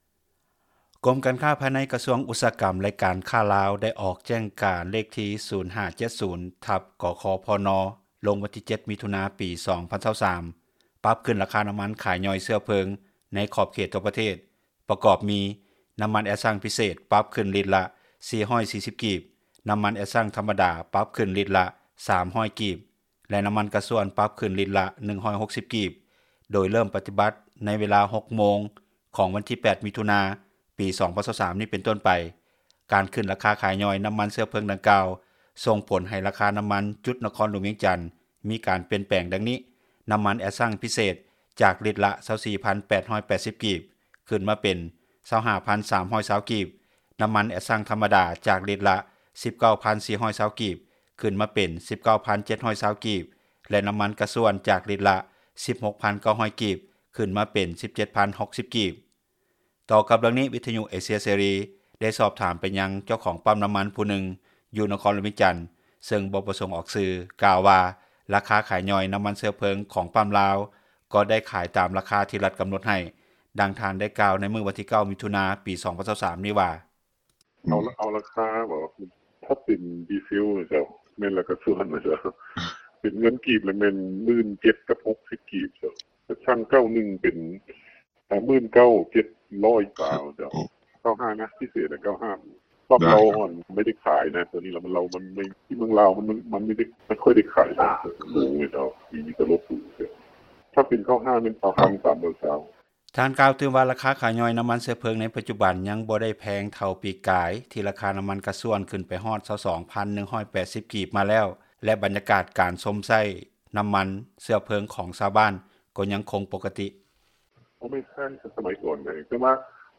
ຕໍ່ກັບເຣື່ອງນີ້, ວິທຍຸ ເອເຊັຽ ເສຣີ ໄດ້ສອບຖາມໄປຍັງ ເຈົ້າຂອງປ້ຳນ້ຳມັນ ຜູ້ນຶ່ງ ຢູ່ນະຄອນຫຼວງວຽງຈັນ ເຊິ່ງບໍ່ປະສົງອອກຊື່ ກ່າວວ່າ ລາຄາຂາຍຍ່ອຍ ຂອງປ້ຳແຫ່ງນີ້ ໄດ້ຂາຍຕາມລາຄາ ທີ່ຣັຖກຳນົດໃຫ້.